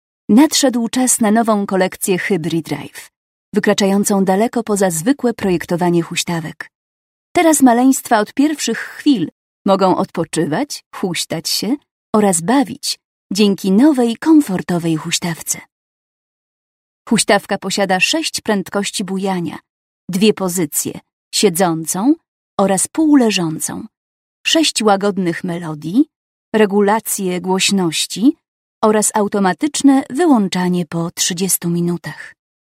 Female 30-50 lat
Narration / filmfrom 30 EUR